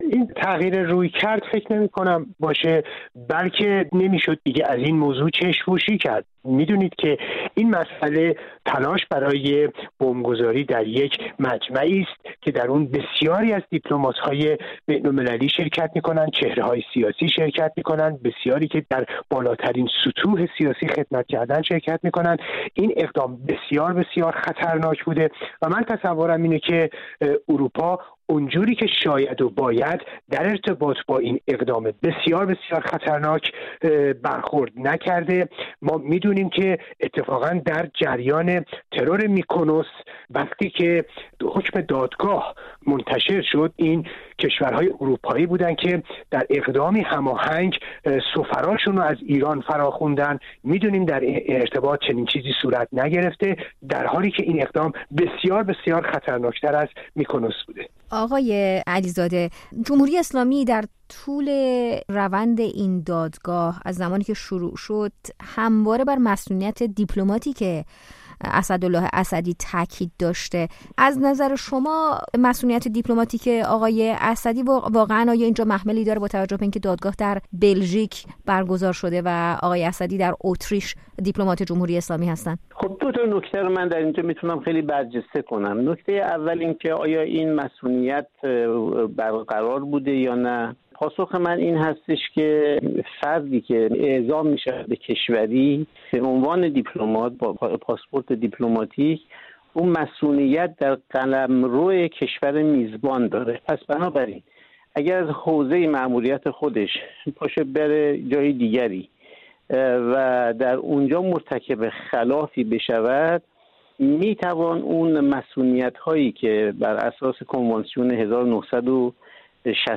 میزگرد